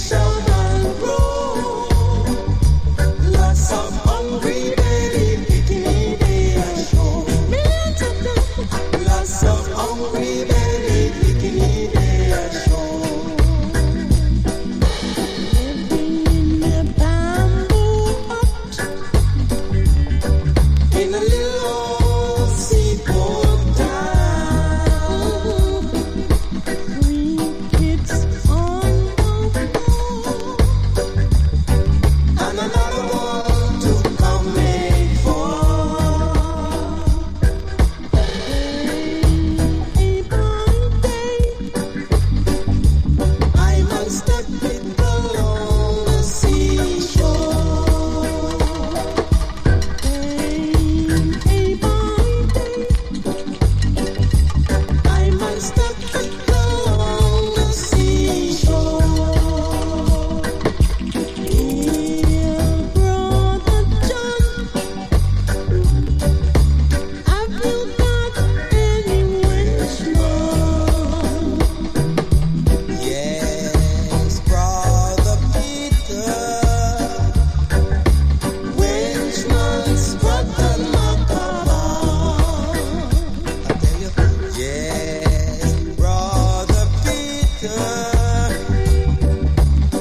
# ROOTS